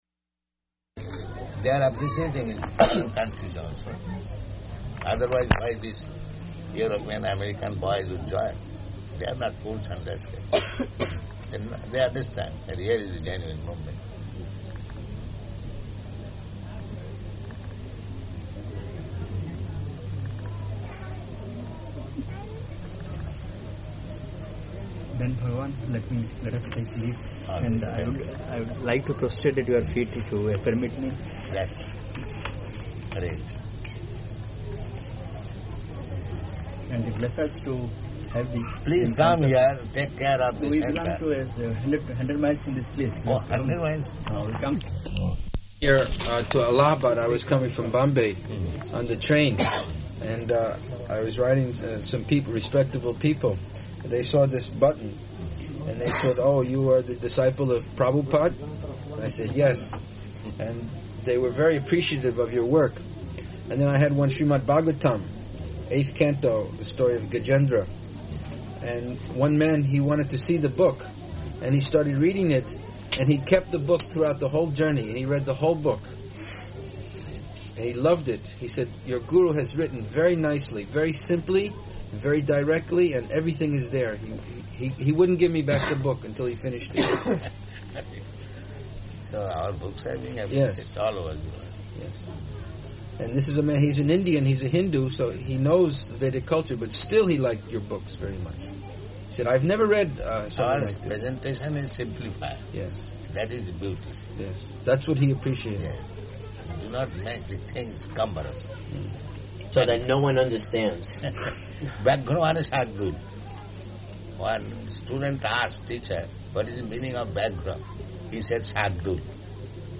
Room Conversation
Room Conversation --:-- --:-- Type: Conversation Dated: January 22nd 1977 Location: Bhubaneswar Audio file: 770122R3.BHU.mp3 Prabhupāda: They are appreciating in foreign countries also.